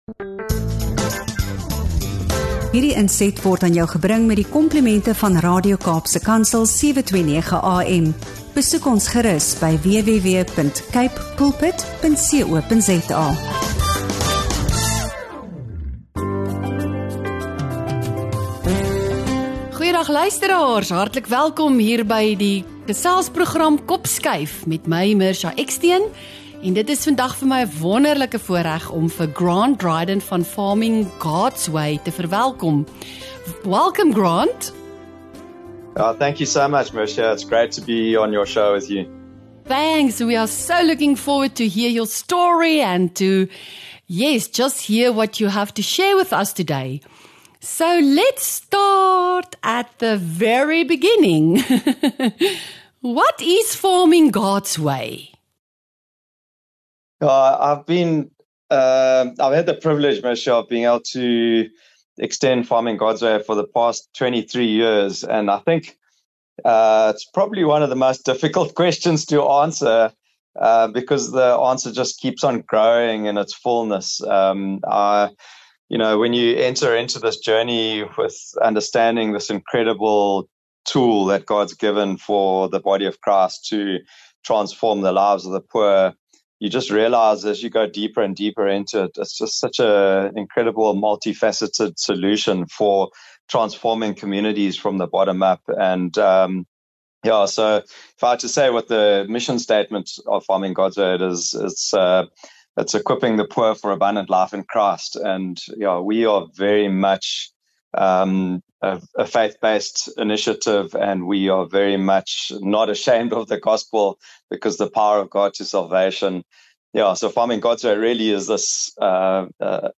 ’n Gesprek